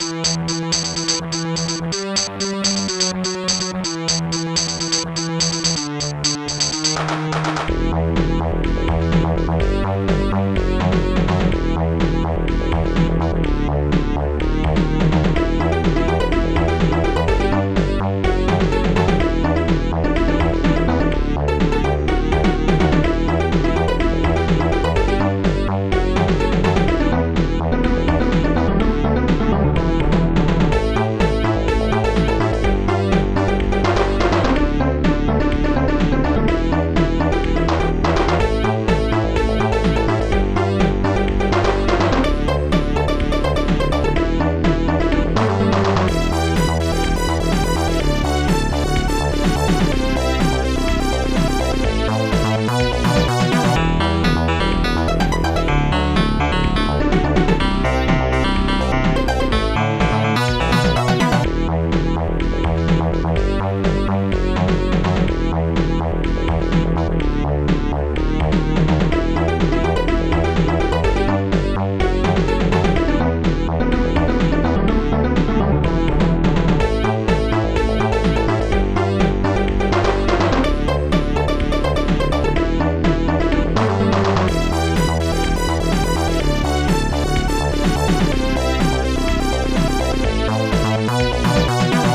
st-01:analogstring
st-01:monobass
st-01:steinway
st-01:bassdrum2
st-01:popsnare1
st-01:hihat1
st-01:claps1